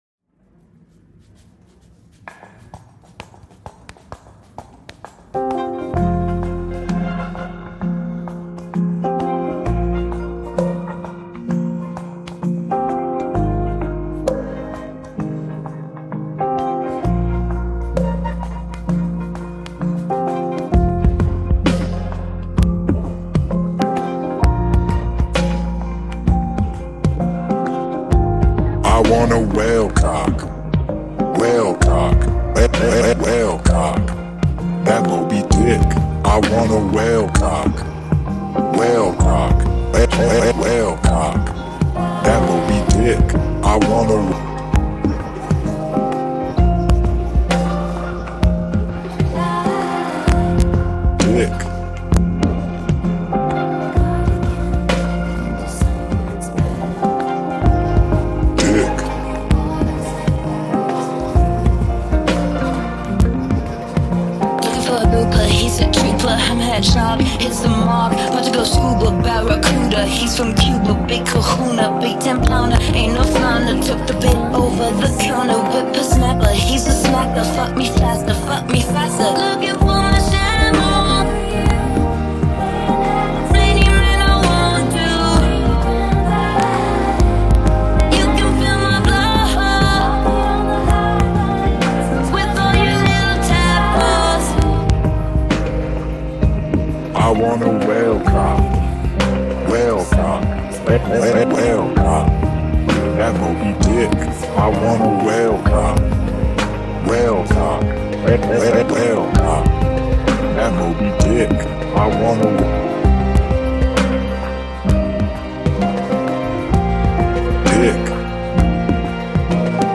mashed up
the result is exactly as silly as you might expect